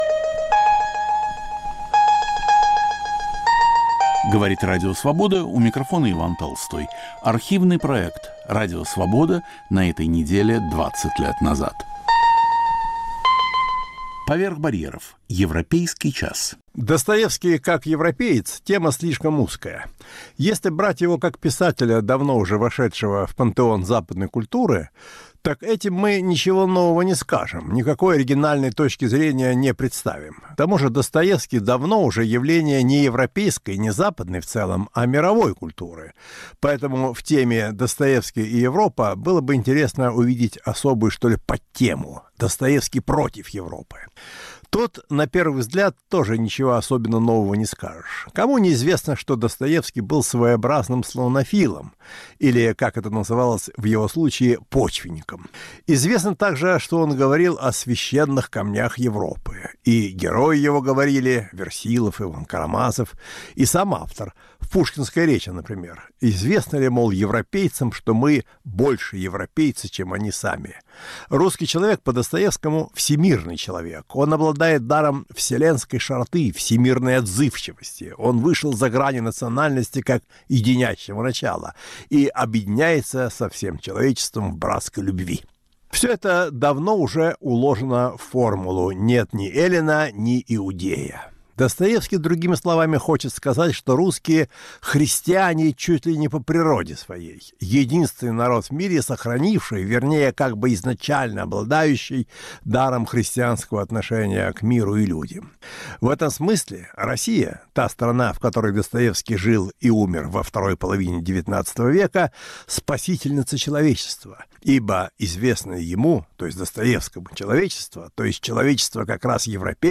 Русский европеец - Федор Достоевский. Редактор и ведущий Иван Толстой.